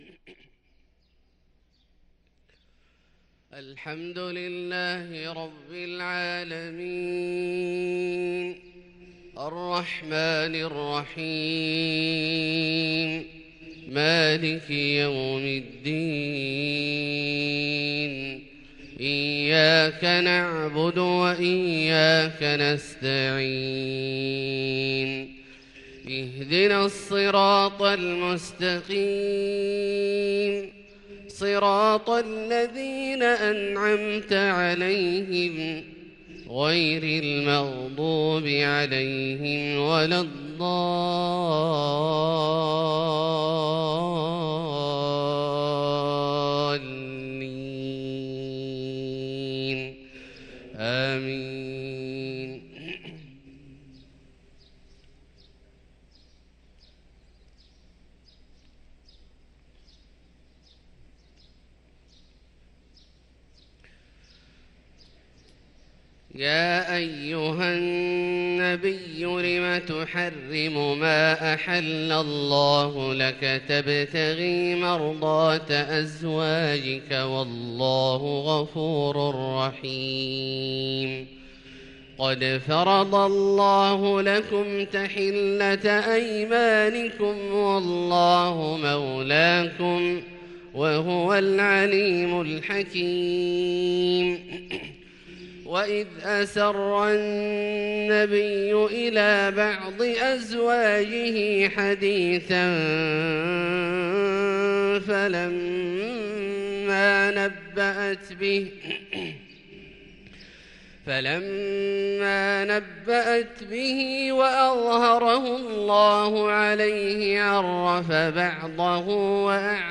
صلاة الفجر للقارئ عبدالله الجهني 11 جمادي الأول 1444 هـ